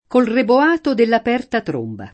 [ rebo # to ]